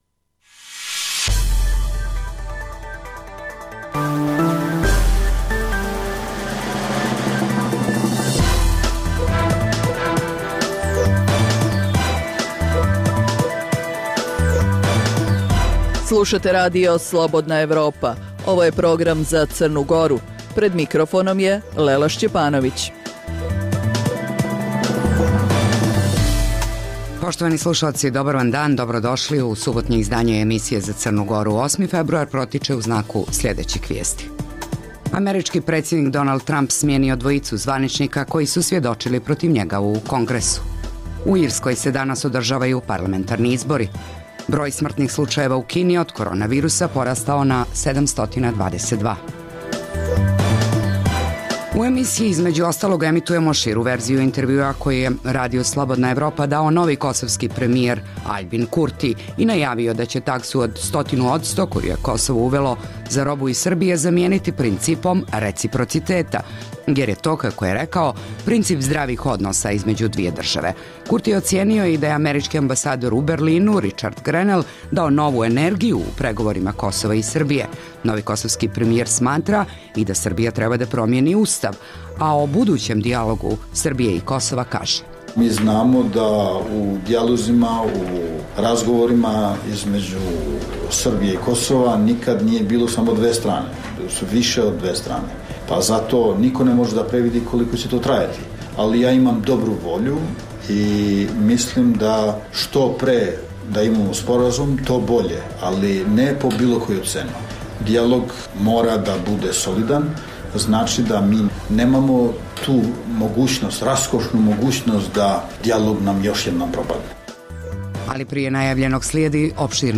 Iz emisije: Poslušajte intervju koji je Radiju Slobodna Evropa dao novi kosovski premijer Aljbin Kurti. U požaru je sinoć izgorjelo sedam stambenih baraka u naselju Rudeš u Beranama. Premijerka Srbije Ana Brnabić uputila je apel Crnoj Gori da dođe do dijaloga o Zakonu o slobodi vjeroispovijesti.